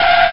squeel3.ogg